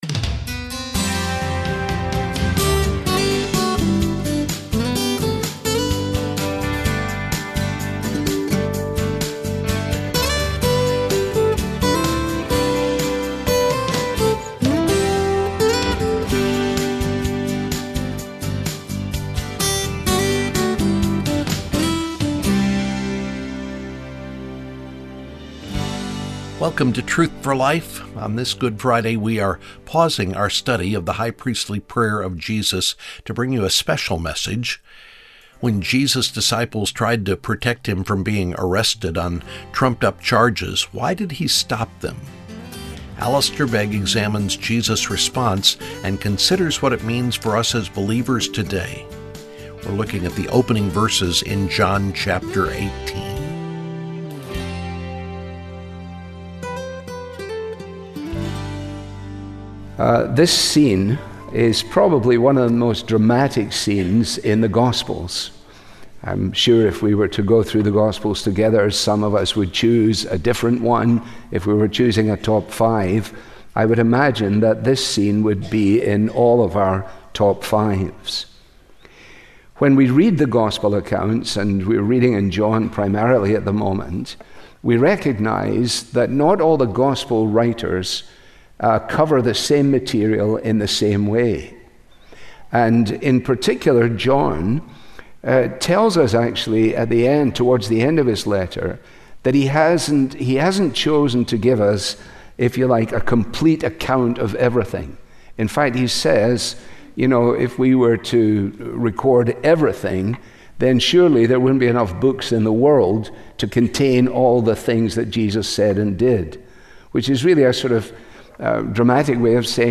• This program is part of a special sermon ‘Shall I Not Drink the Cup?’